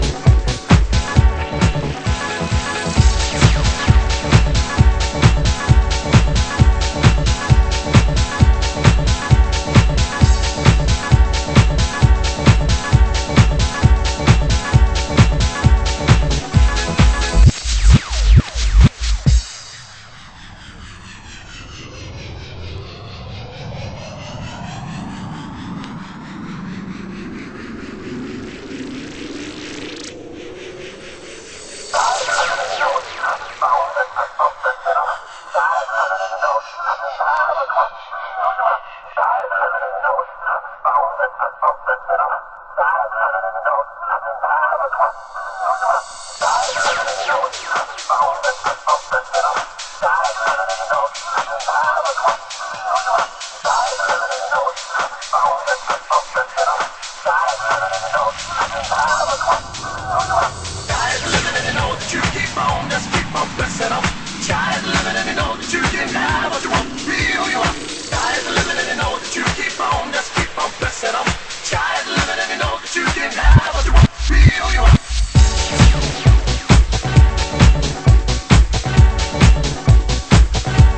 Club Vocal
盤質：小傷、スレ傷により、少しチリパチノイズ有